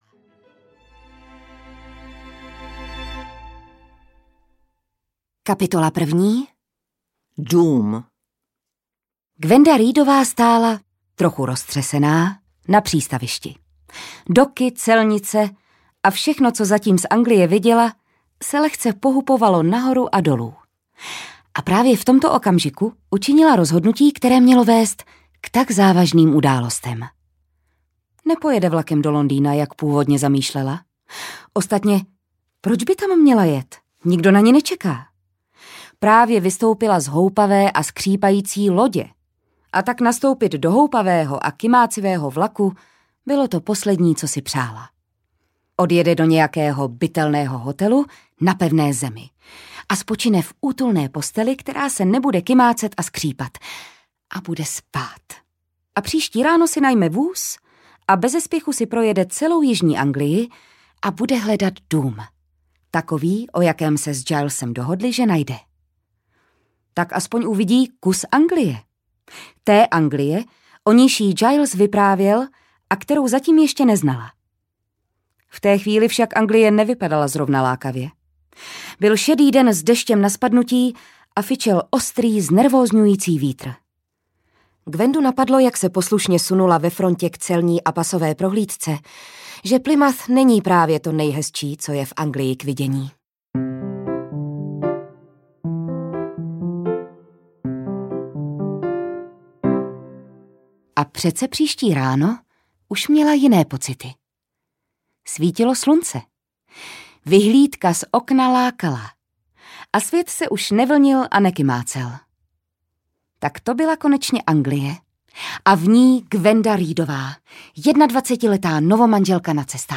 Ukázka z knihy
• InterpretRůžena Merunková, Jitka Ježková, Jan Meduna